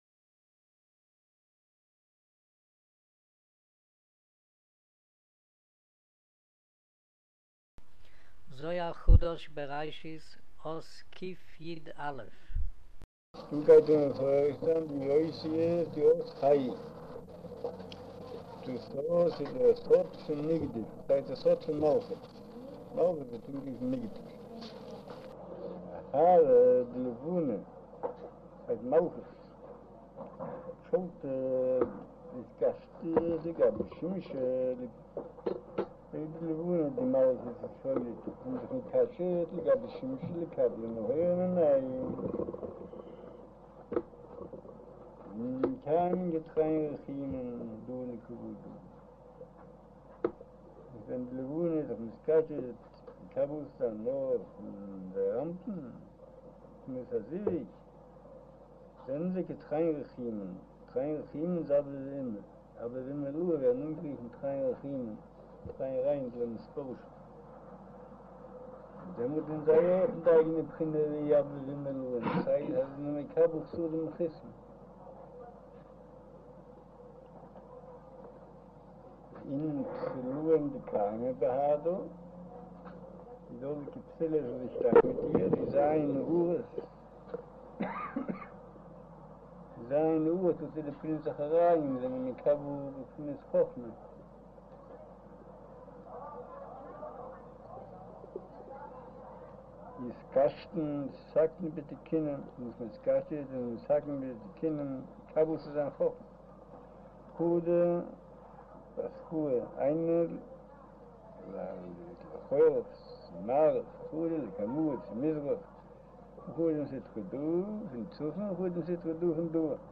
אודיו - שיעור מבעל הסולם זהר חדש בראשית אות קיא' - קיט'